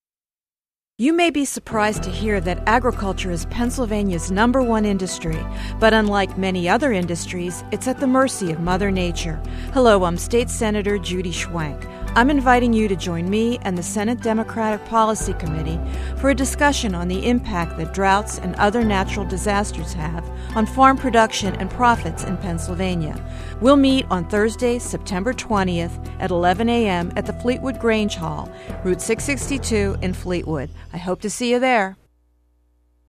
Senator Schwank and the Senate Democratic Policy Committee will hold a public hearing on agricultural issues this month in Fleetwood. Here’s more with Senator Schwank.
schwank-policy-hearing-fleetwood-psa-1.mp3